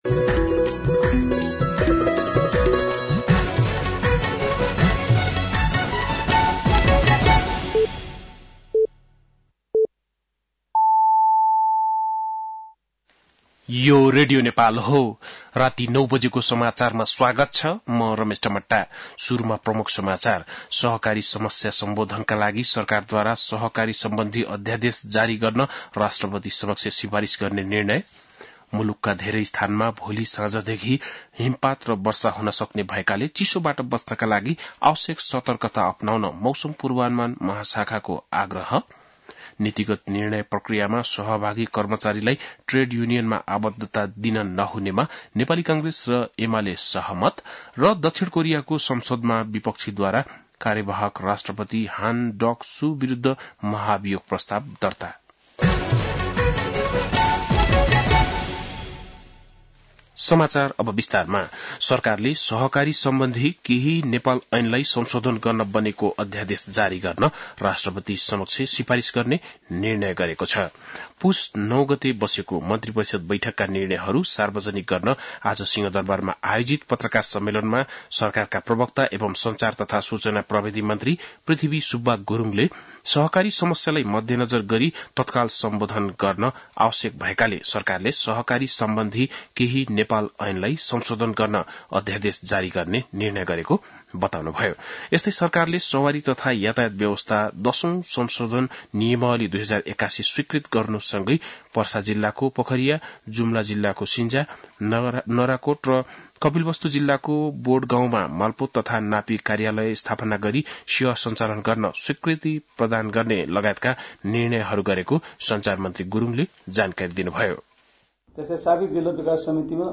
बेलुकी ९ बजेको नेपाली समाचार : १३ पुष , २०८१